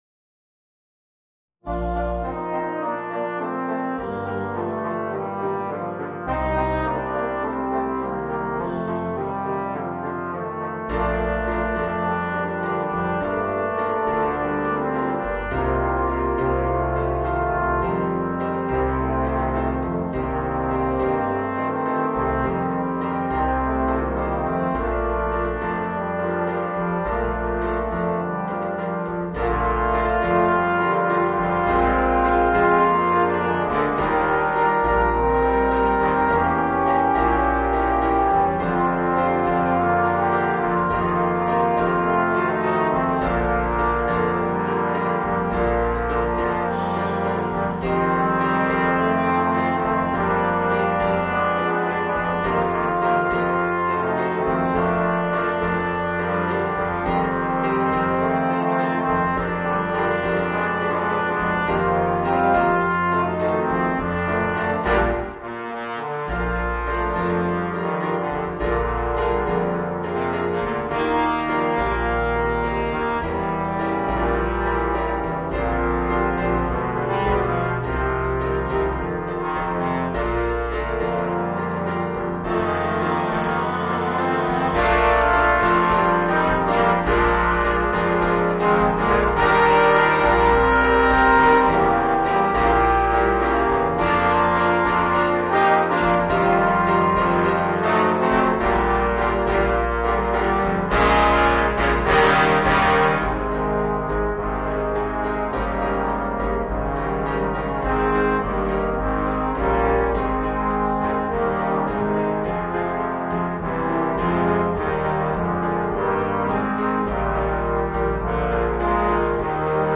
Voicing: 4 Trombones and Rhythm Section